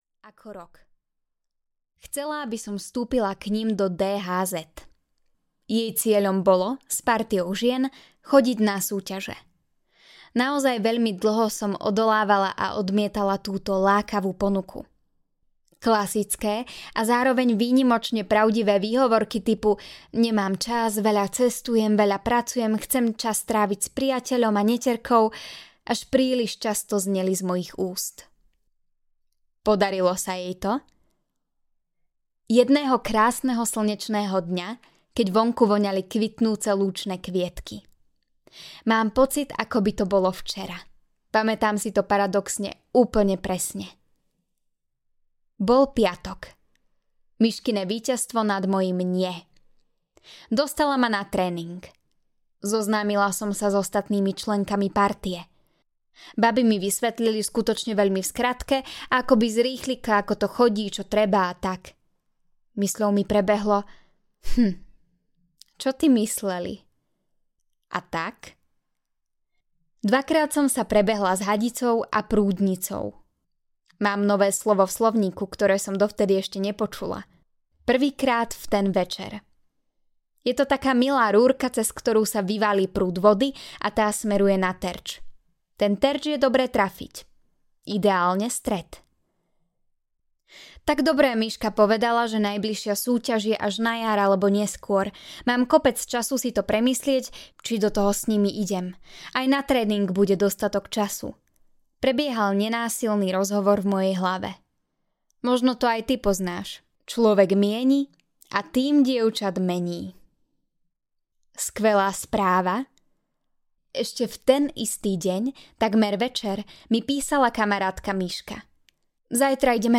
Život s úsmevom audiokniha
Ukázka z knihy
Nahovorená je tak aby vás to bavilo počúvať.
zivot-s-usmevom-audiokniha